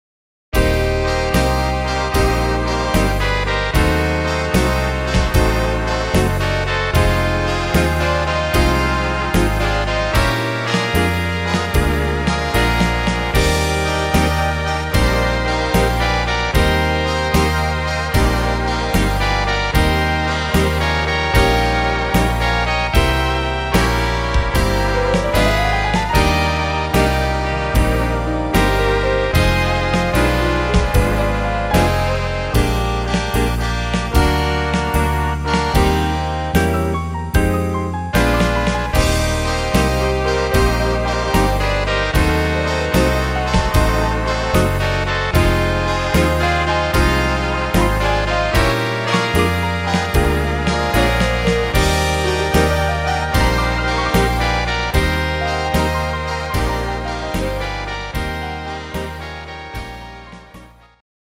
instr. Big Band